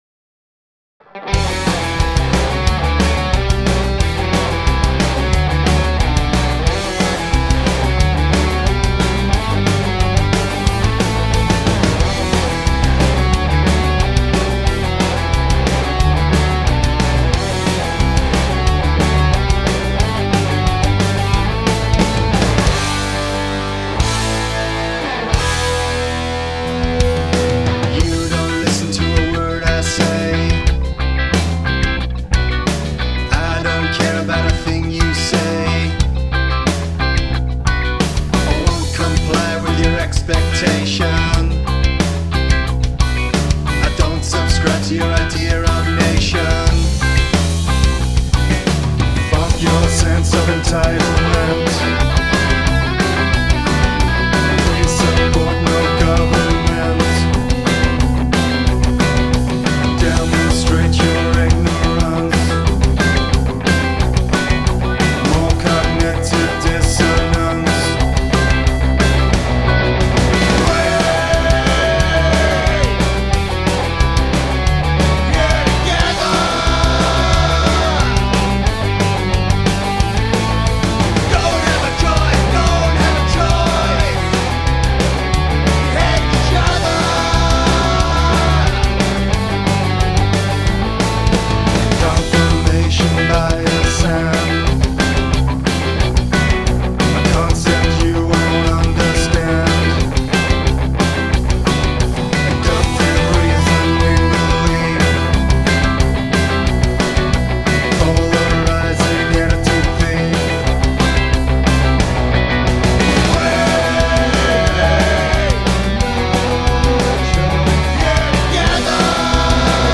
I think you've still got reverb abuse happening. The whole thing sounds like it's in a box. It's still dark-ish. The mix sounds very dated, like it's off an old skate rock compilation, but that's not necessarily a bad thing. The levels are good for the most part. That little lead line that comes in the verses is a little loud and/or unnecessary.
They'e pretty rough.